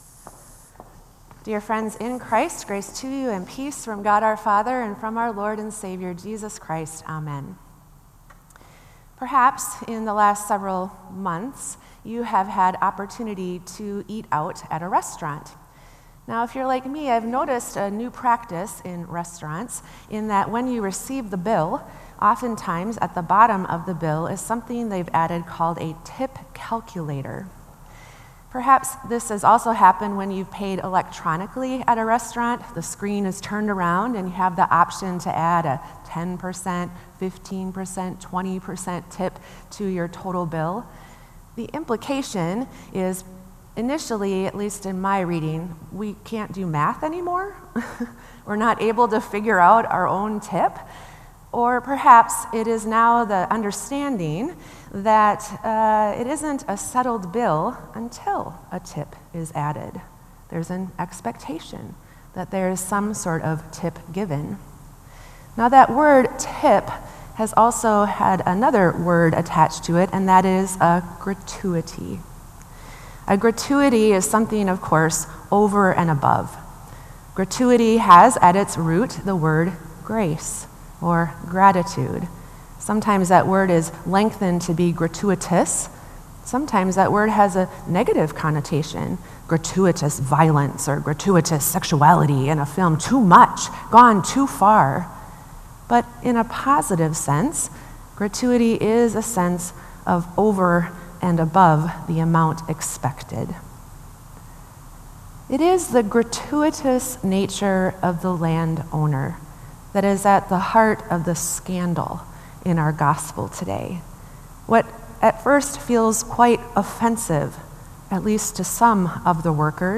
Sermon “Unexpected Extravagance”